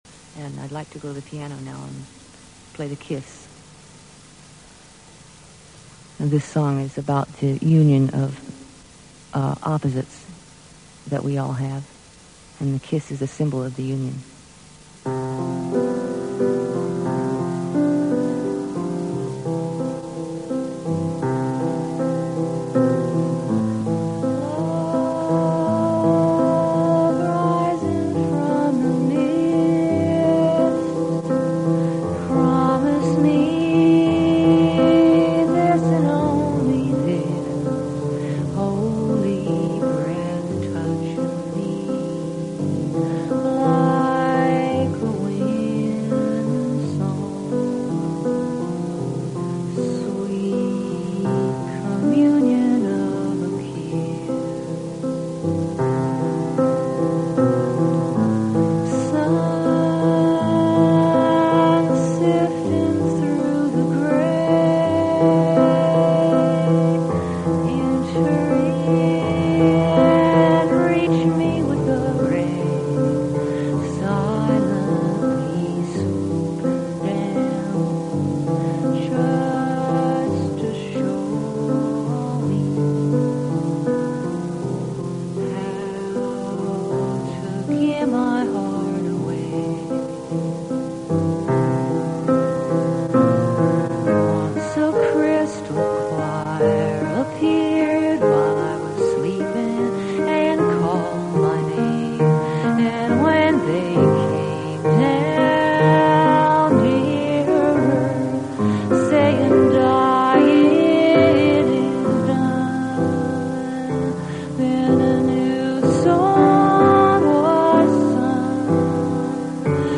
(again, TV recorded with a microphone)